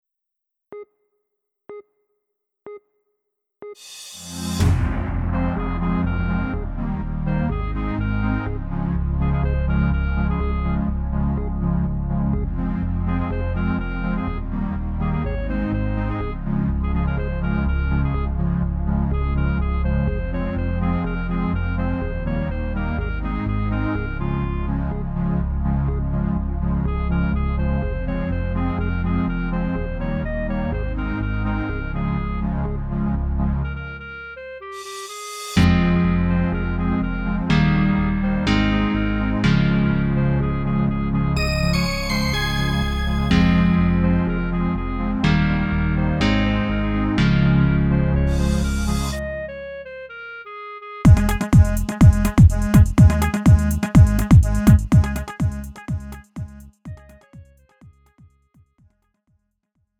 음정 원키 2:42
장르 가요 구분 Lite MR